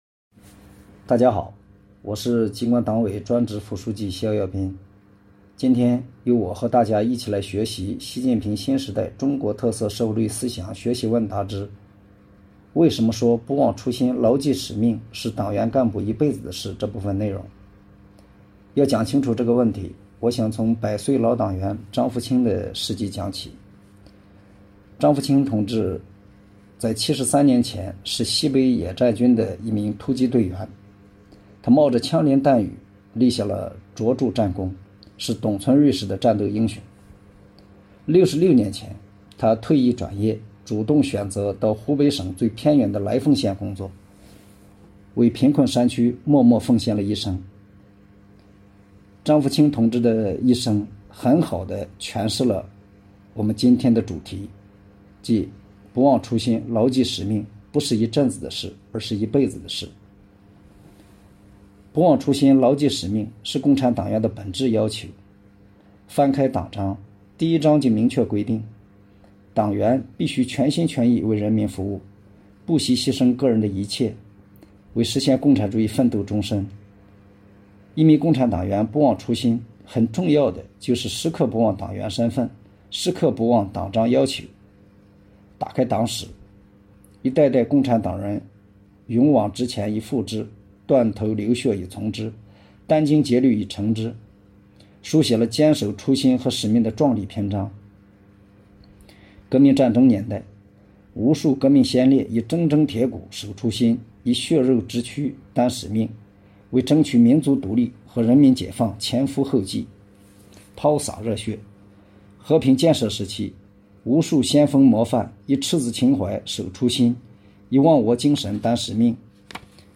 为深入贯彻落实海南省党史学习教育实施方案通知精神，机关党委以“空中党课”形式，开展党员领读党章党规、习近平总书记系列重要讲话等“微党课”活动，促进党员干部教育“全覆盖”，切实推动党史学习教育常态化制度化落到实处。下面，播放的是第一期“空中党课”，内容节选自《习近平新时代中国特色社会主义思想学习问答》之：对党员干部来讲，为什么说不忘初心、牢记使命是一辈子的事？